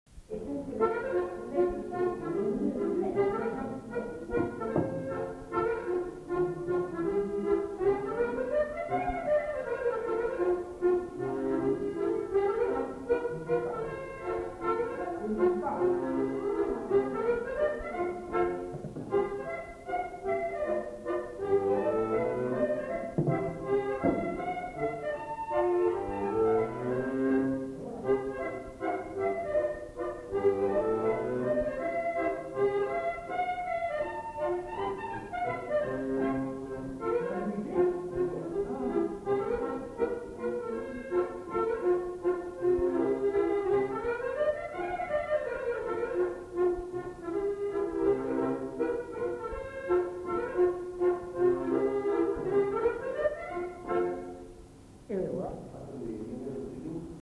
enquêtes sonores
Mazurka